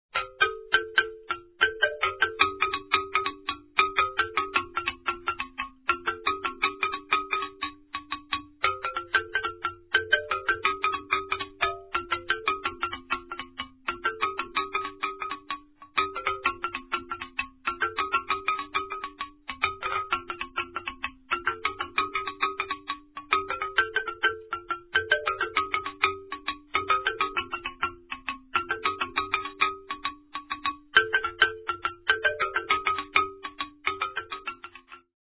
noted world percussionist
traditional gyil playing